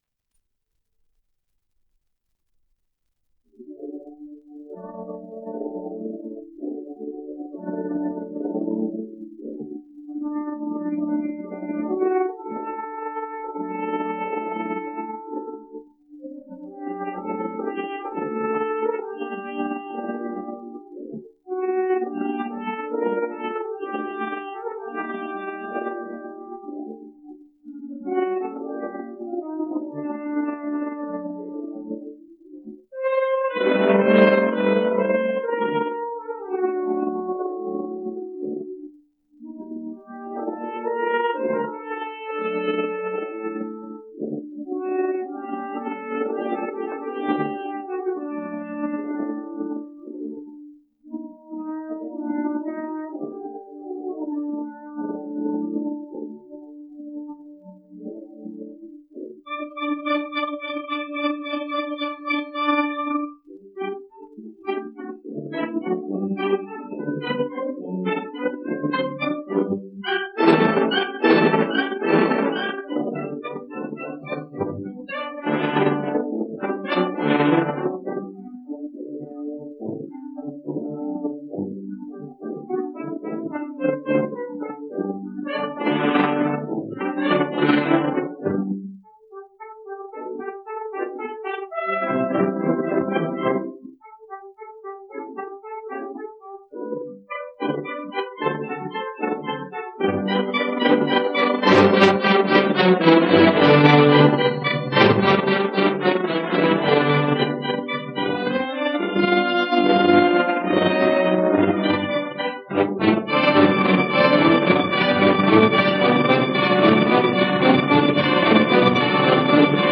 La Tempranica : Fantasía, parte II (sonido remasterizado)